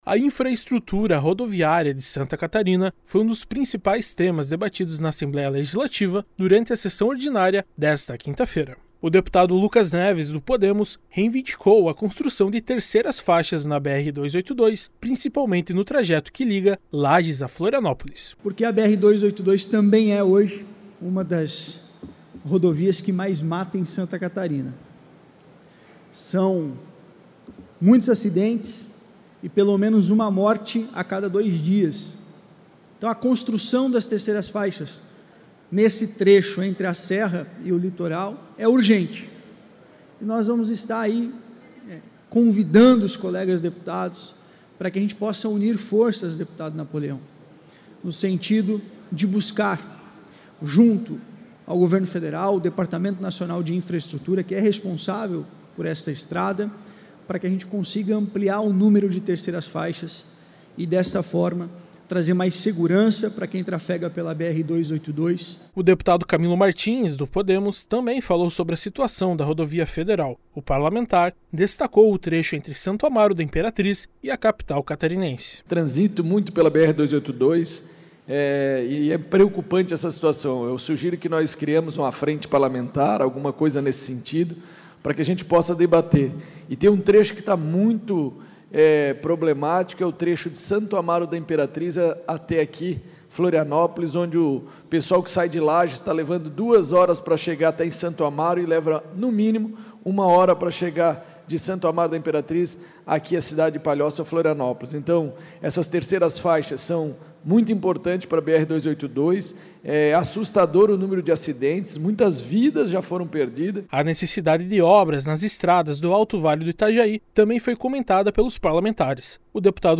Entrevista com:
- deputado Lucas Neves (Podemos);
- deputado Camilo Martins (Podemos);